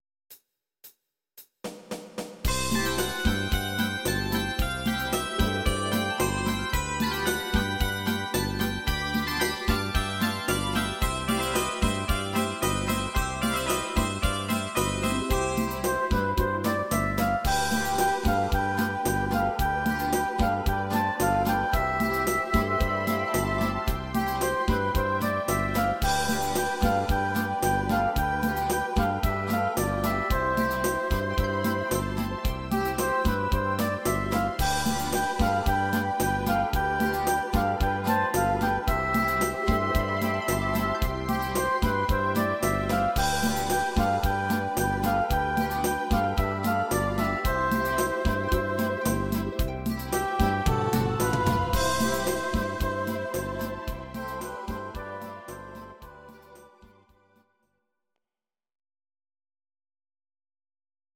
Audio Recordings based on Midi-files
German, 1960s